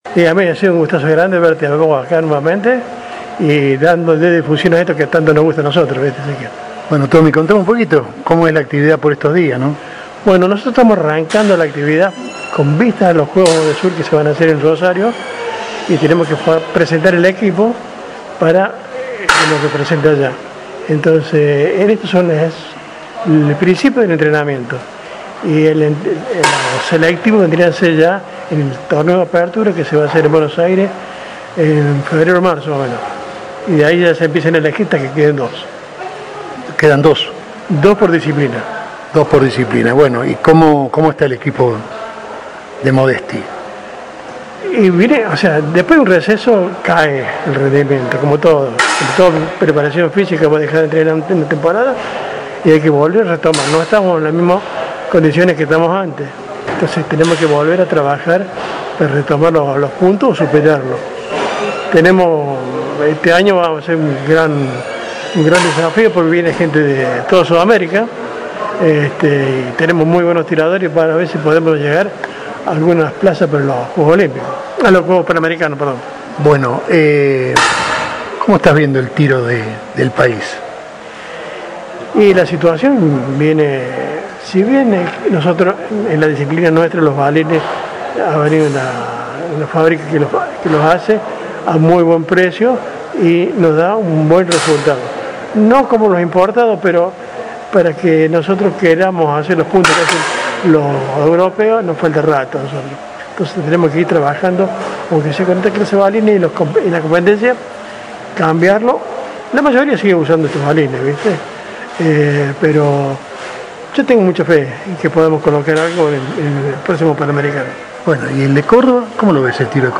Audio nota